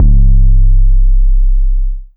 Rough 808.wav